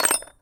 metal_small_movement_17.wav